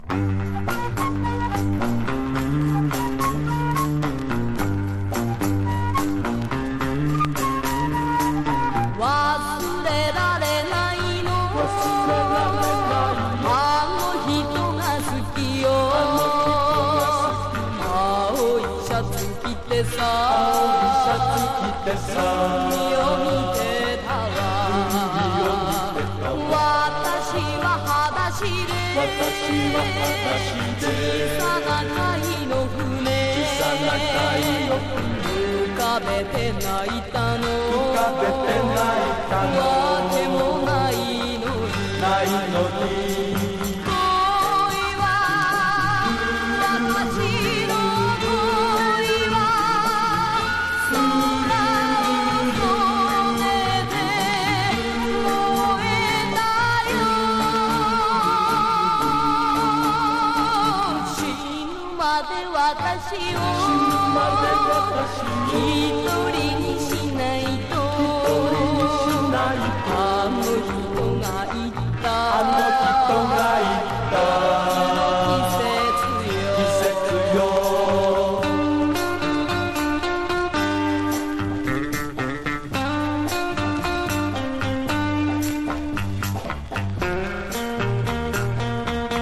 和モノ / ポピュラー
薄スレ等が少しあるが、リスニングに問題なし。